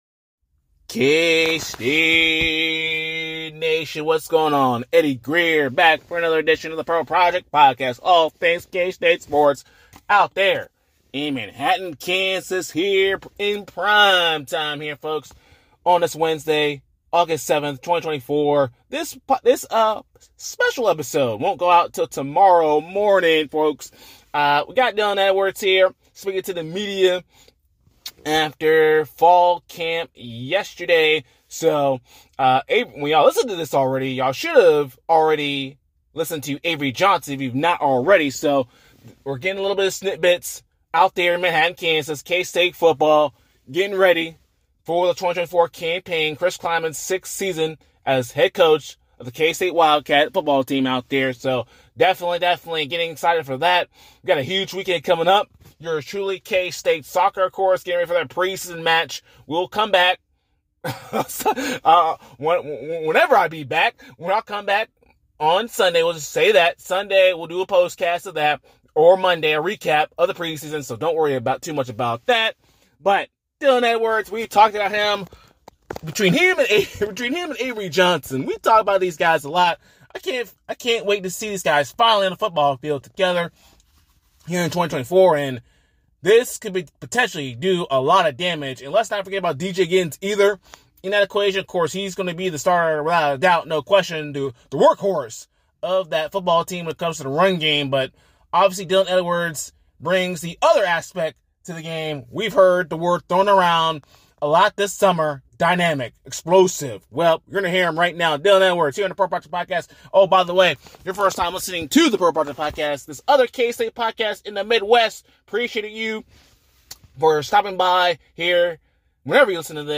spoke to the media this week at Fall Camp ahead of the 2024 football season!